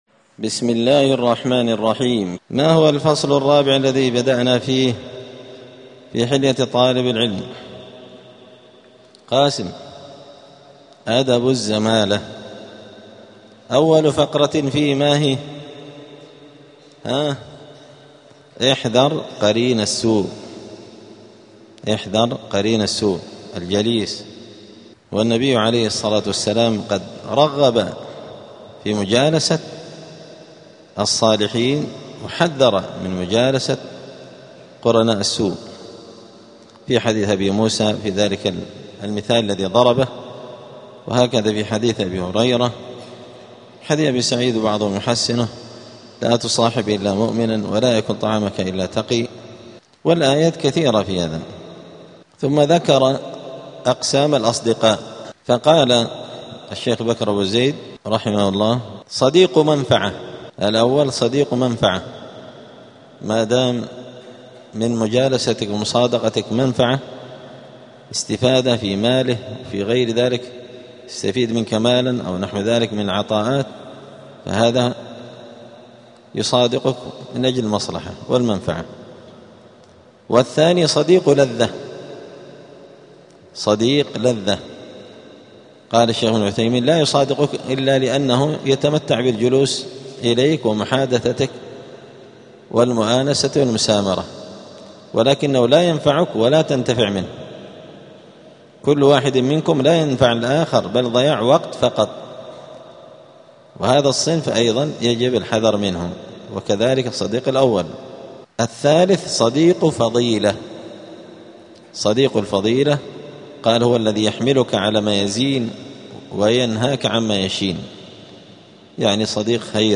*الدرس التاسع والأربعون (49) {أنواع الأصدقاء}.*
السبت 3 جمادى الأولى 1447 هــــ | الدروس، حلية طالب العلم، دروس الآداب | شارك بتعليقك | 10 المشاهدات